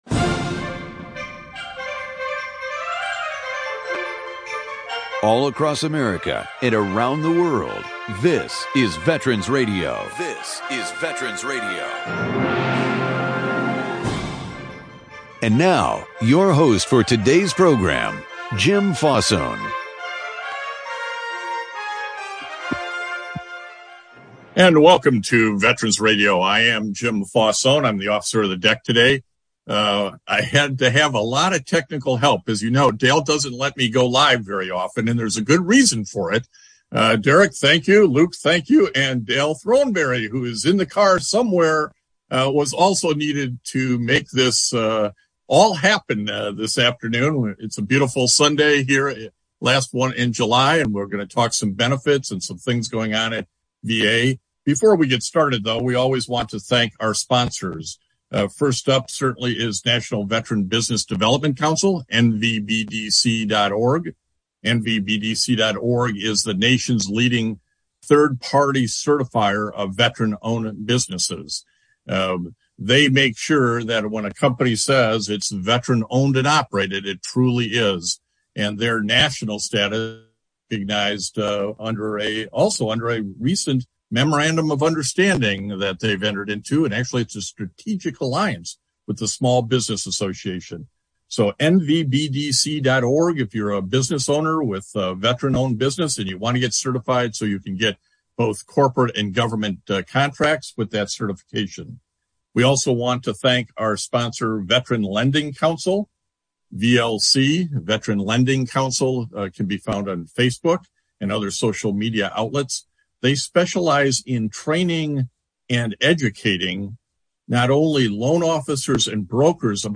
Live Q&A for Call-in to Ask YOUR question about VA Benefits.